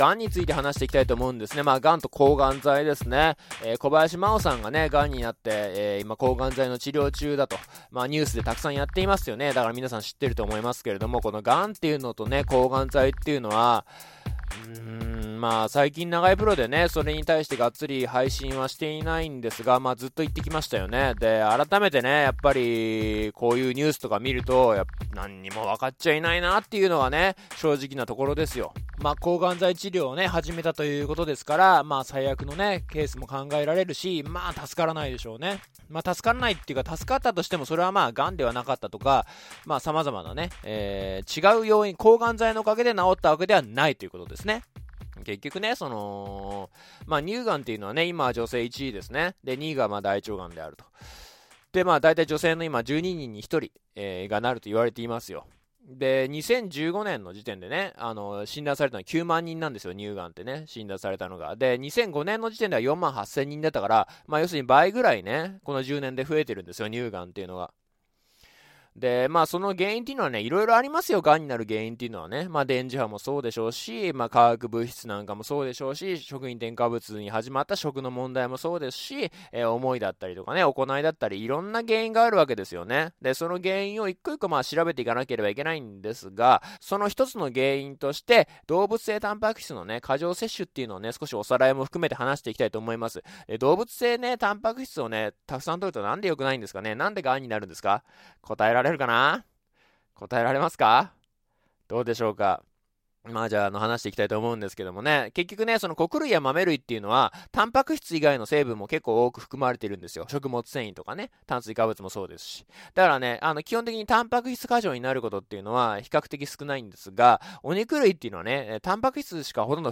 ※2016年6月13日に配信されたラジオです。